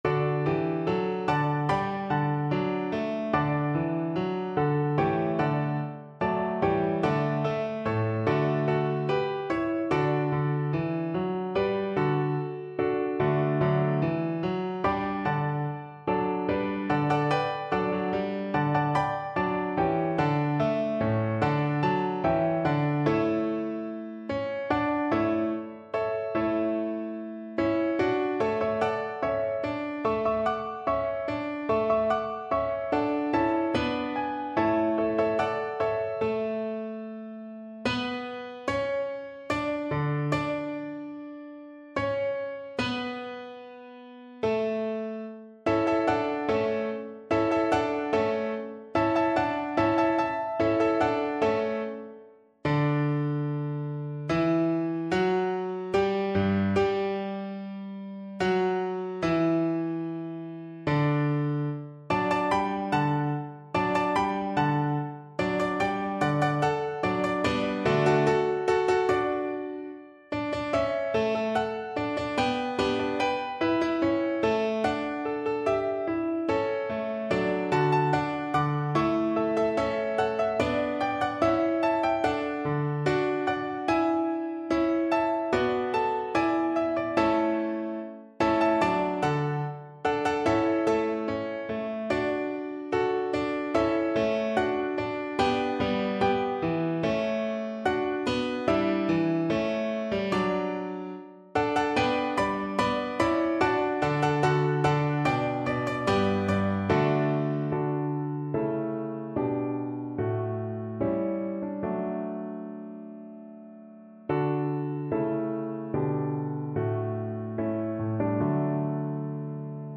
Viola
D major (Sounding Pitch) (View more D major Music for Viola )
4/4 (View more 4/4 Music)
Allegro (View more music marked Allegro)
Classical (View more Classical Viola Music)
Baroque Music for Viola
handel_hallelujah_VLA_kar3.mp3